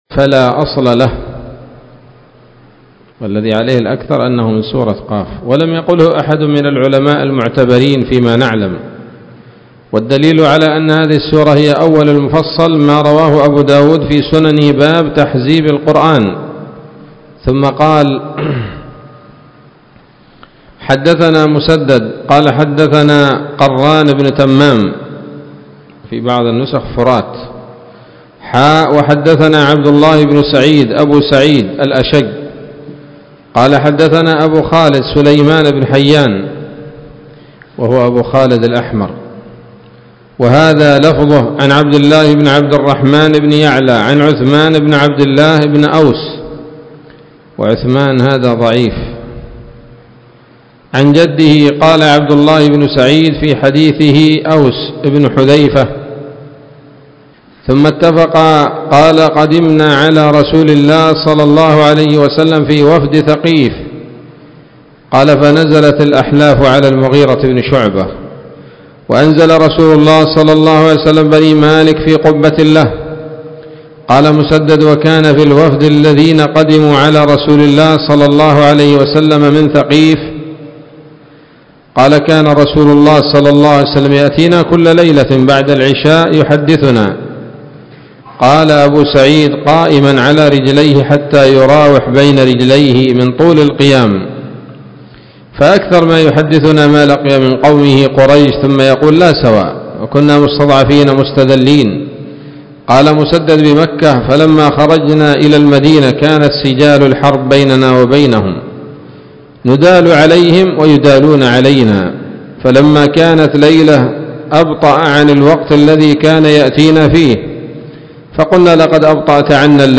الدرس الأول من سورة ق من تفسير ابن كثير رحمه الله تعالى